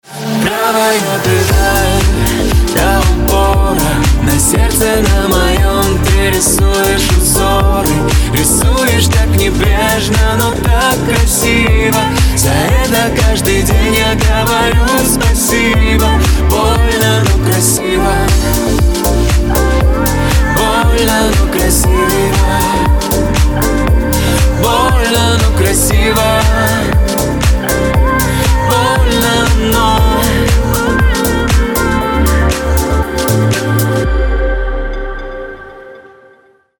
• Качество: 320, Stereo
поп
мужской вокал
женский вокал
грустные